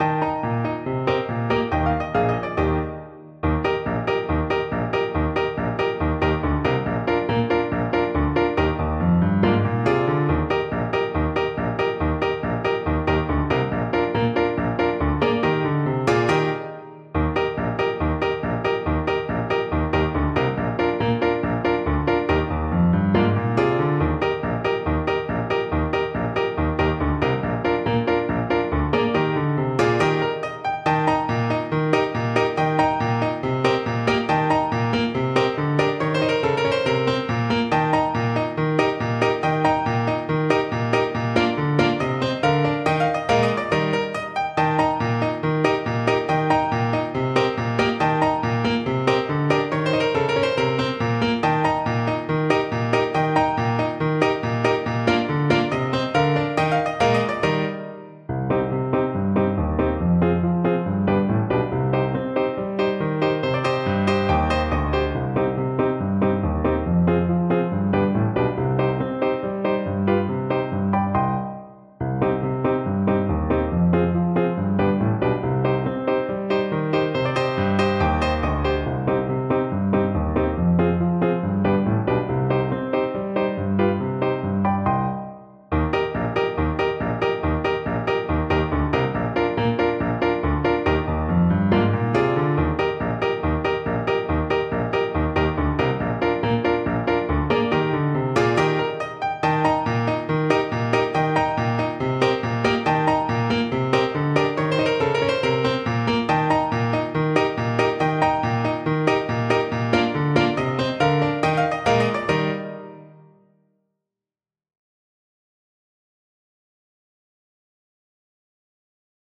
Trombone
Eb major (Sounding Pitch) (View more Eb major Music for Trombone )
Allegro =c.140 (View more music marked Allegro)
2/4 (View more 2/4 Music)
Traditional (View more Traditional Trombone Music)